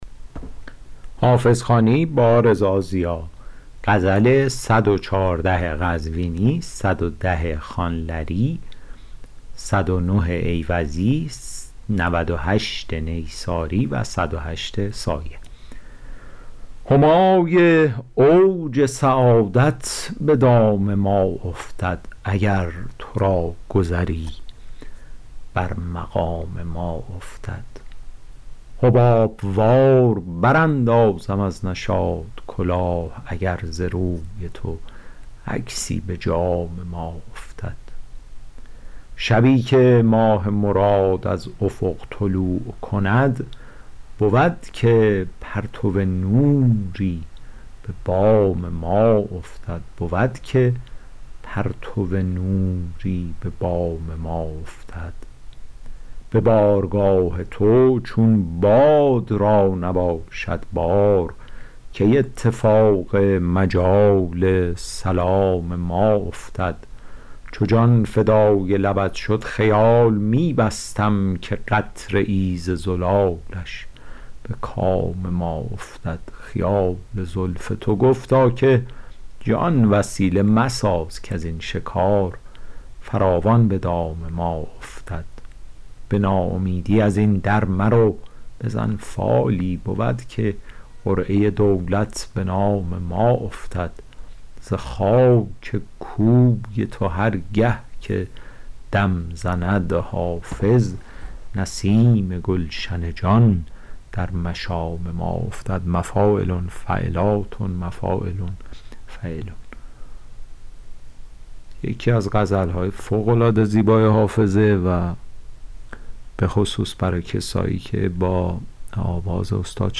حافظ غزلیات شرح صوتی